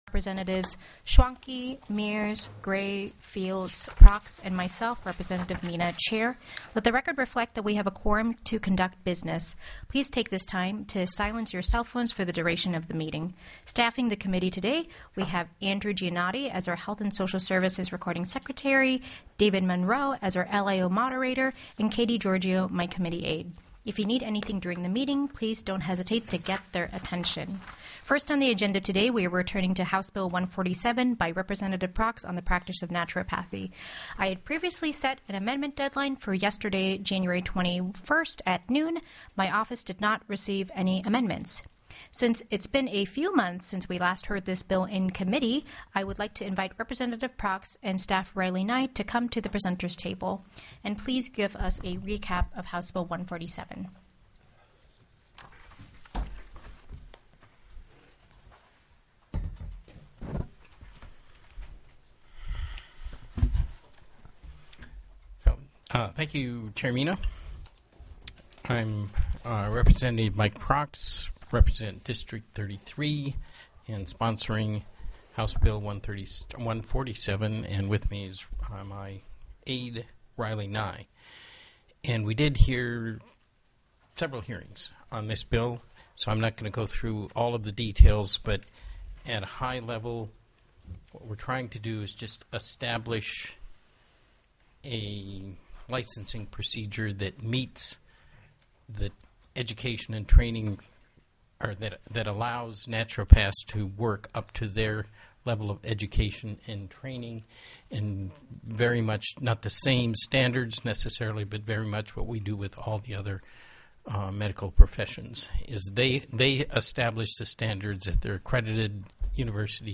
01/22/26 (H) HSS AT 3:15 PM DAVIS 106 WITNESS REGISTER REPRESENTATIVE MIKE PRAX Alaska State Legislature Juneau, Alaska POSITION STATEMENT: As prime sponsor, presented HB 147. REPRESENTATIVE ANDREW GRAY Alaska State Legislature Juneau, Alaska POSITION STATEMENT: As prime sponsor, presented HB 232 SENATOR CATHY GIESSEL Alaska State Legislature Juneau, Alaska POSITION STATEMENT: Provided invited testimony during the hearing on HB 232.
The audio recordings are captured by our records offices as the official record of the meeting and will have more accurate timestamps.